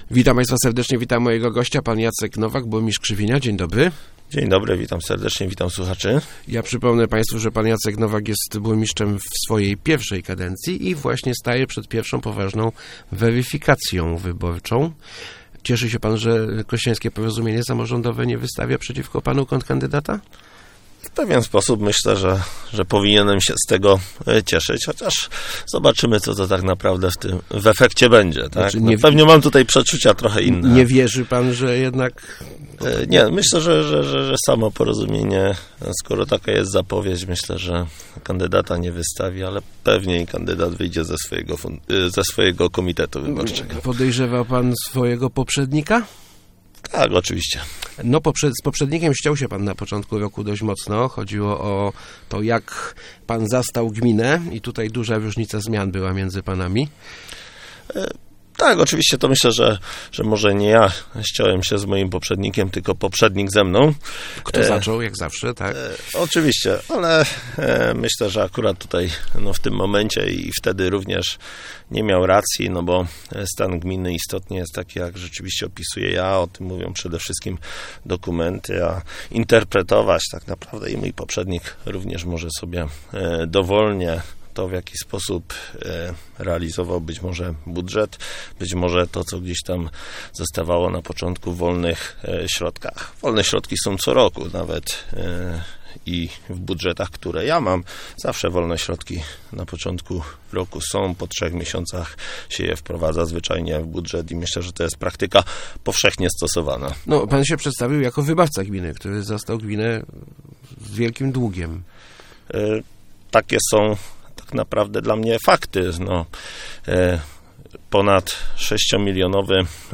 Naszym największym sukcesem ostatnich czterech lat jest ustabilizowanie sytuacji finansowej gminy - mówił w Rozmowach Elki Jacek Nowak, burmistrz Krzywinia.